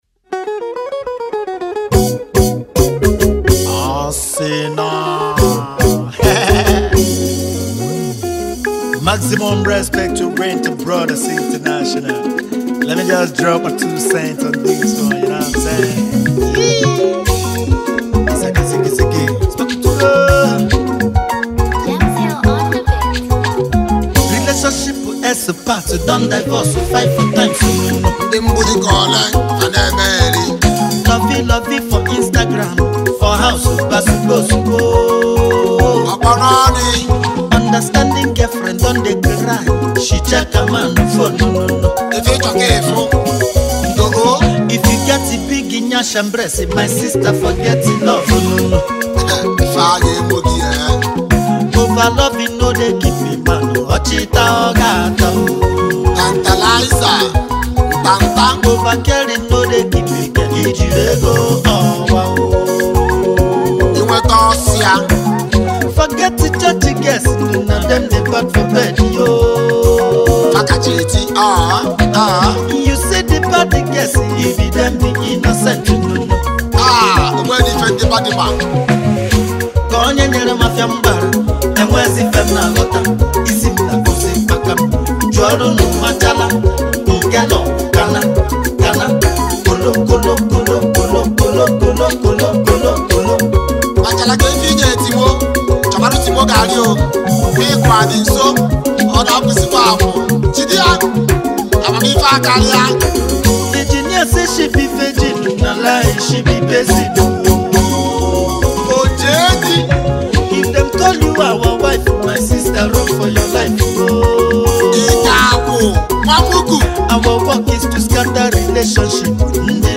electrifying new single
a perfect blend of rhythm, energy,